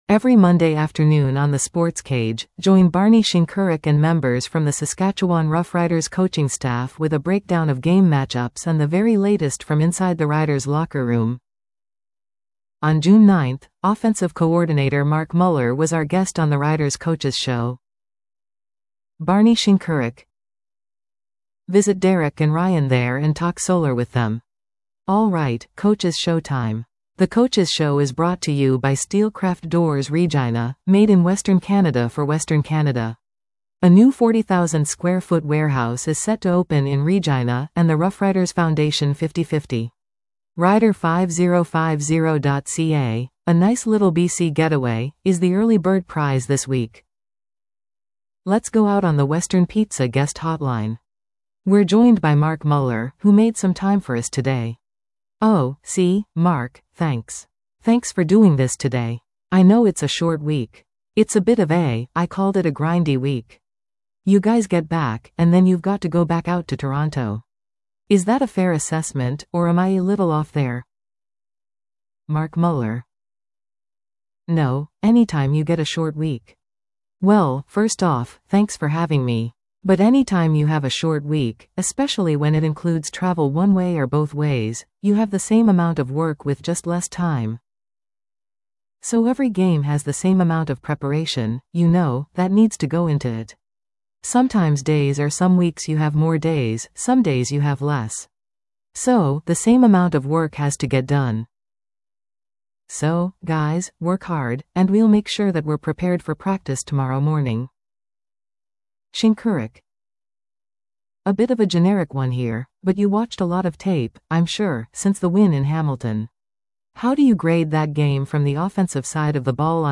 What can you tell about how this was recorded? Let's go out on the Western Pizza Guest Hotline.